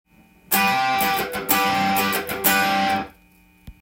Gミクソリディアンスケールを例にフレーズを作ってみました。
②のフレーズは和音系のものになり
Gミクソリディアンスケール上に出来るコードを弾いたフレーズです。